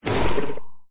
Warden Attack Efecto de Sonido Descargar
Sound Effects Soundboard3 views